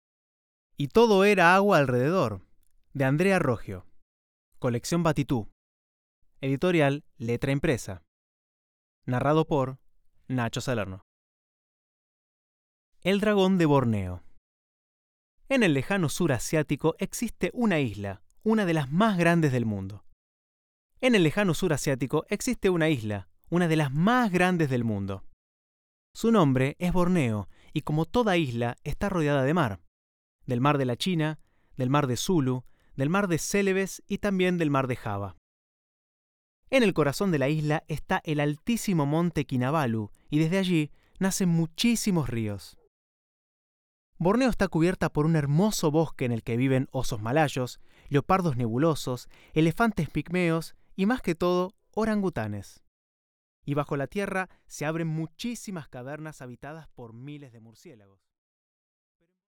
Muestra de audiolibro